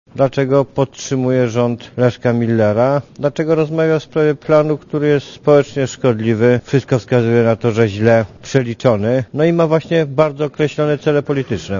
Lider Prawa i Sprawiedliwości Jarosław Kaczyński powiedział podczas konwencji tej partii w Gdańsku, że nie rozumie postawy Platformy Obywatelskiej wobec SLD i planu Hausnera.